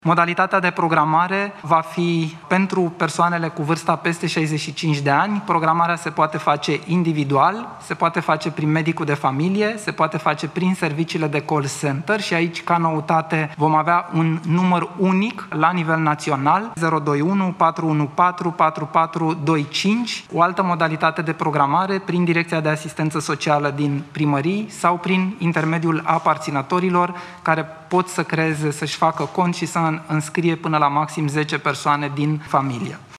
Coordonatorul Campaniei de vaccinare – medicul Valeriu Gheorghita: